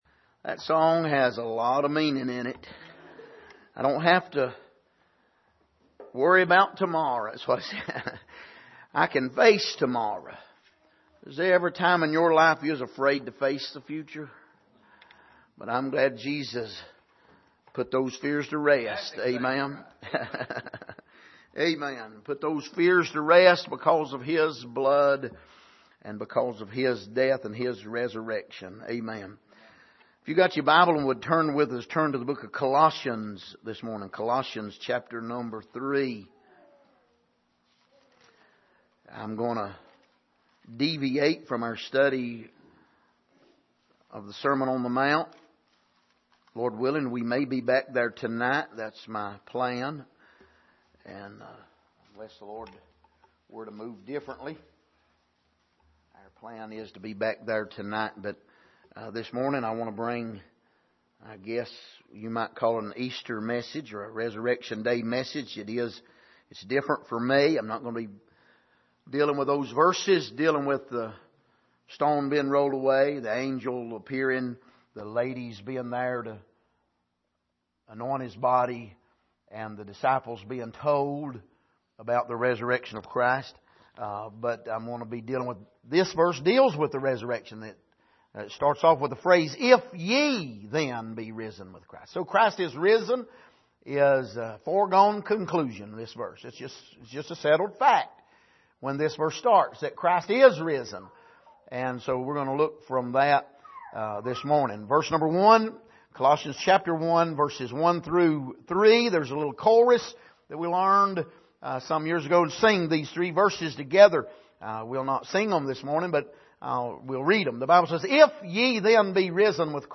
Passage: Colossians 3:1-4 Service: Sunday Morning